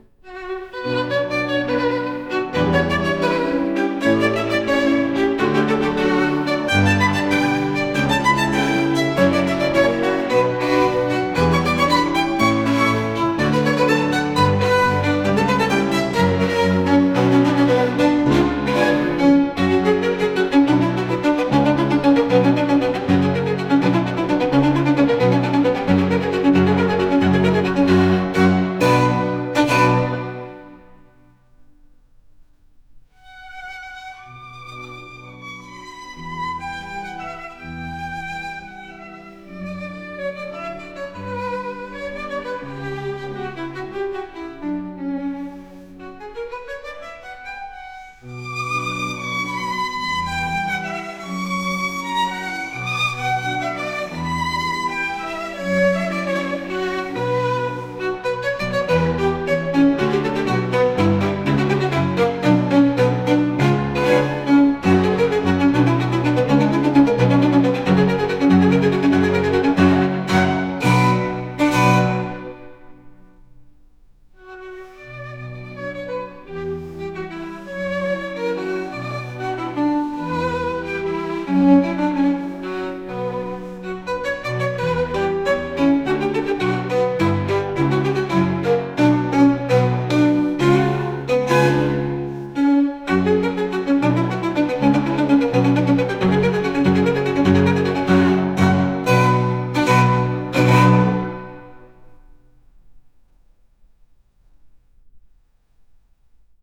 貴族の高貴さを表現するようなバイオリン曲です。